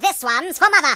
project_files/Data/Sounds/voices/British/Watchthis.ogg
Watchthis.ogg